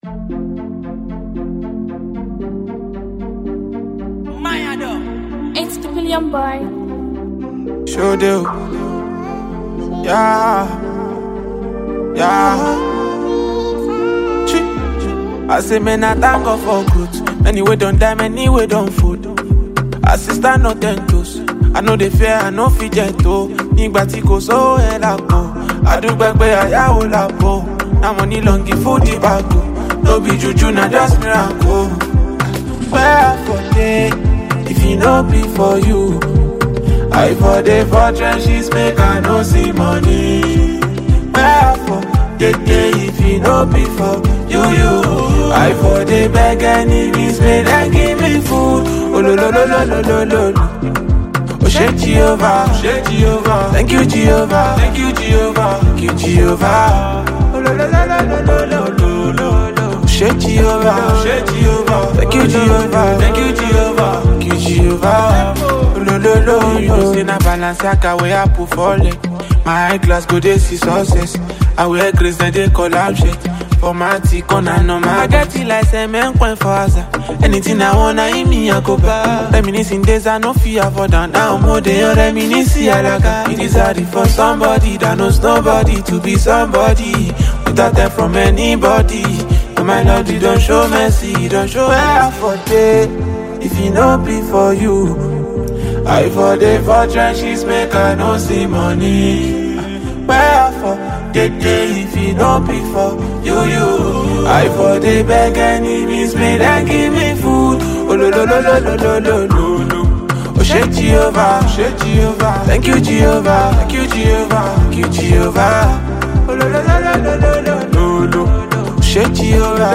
Foreign MusicNaija Music